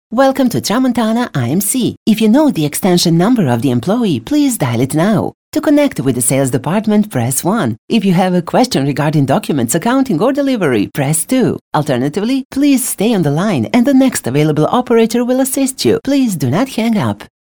Voice Actors for On-Hold Messages & Voicemail | Discover Now
This German voice over is a extremely diverse voice over with lots of experience.